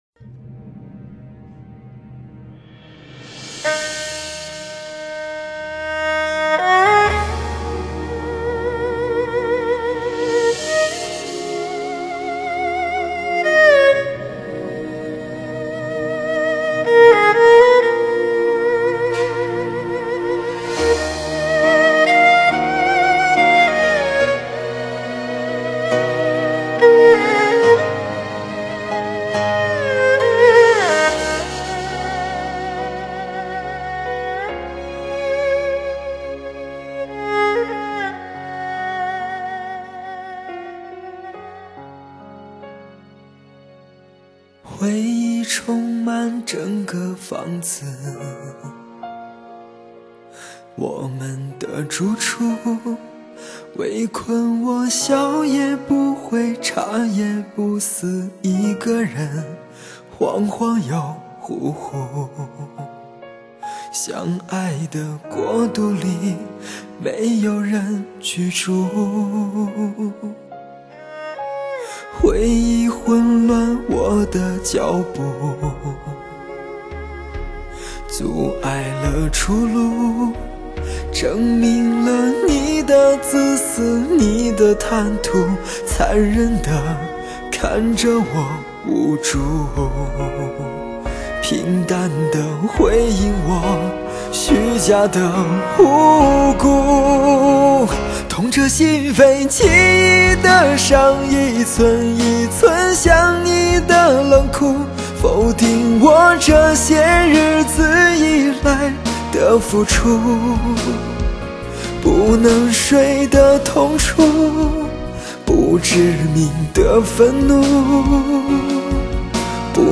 本专辑采用大量的弦乐配衬
耳畔滑出悦耳的弦乐，涌出阵阵酸楚，伤感的磁性嗓音，